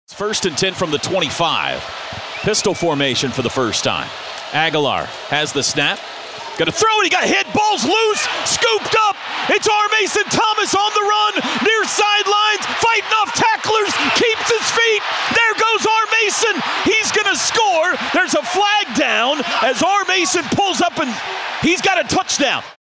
Sooners Roll on KYFM